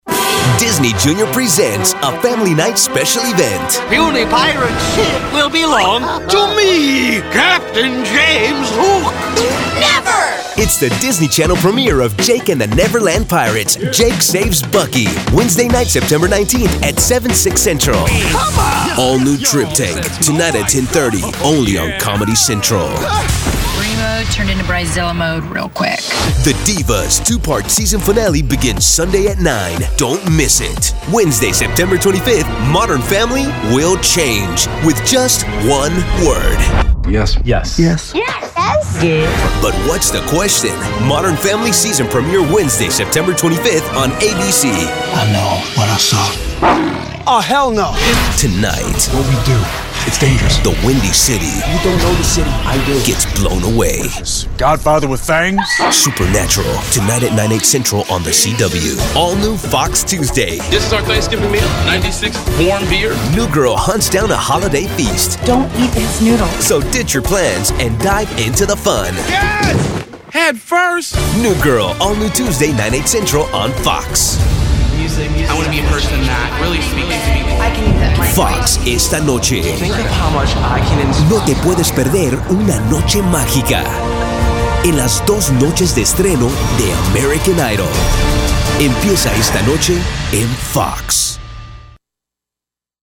All of our contracted Talent have broadcast quality home recording studios.